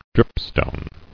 [drip·stone]